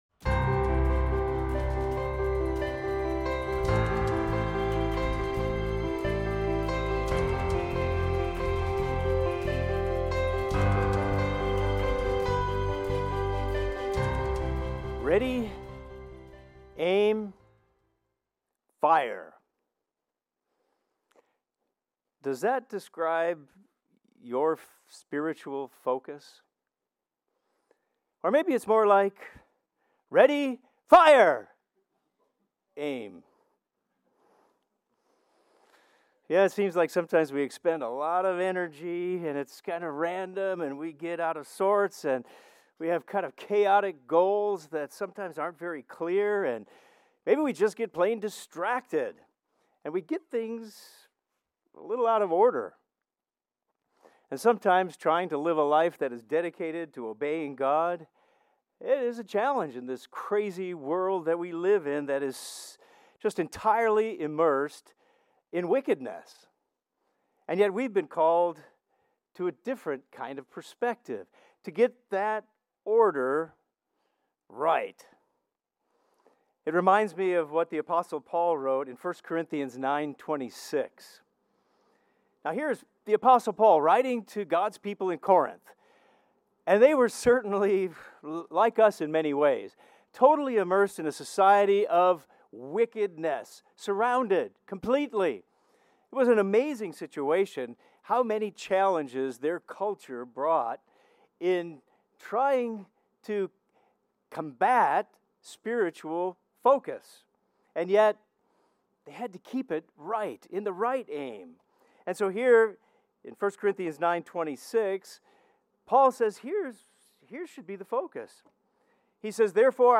How can we take aim consistently at the right spiritual target? This sermon will address how to hit the spiritual bullseye.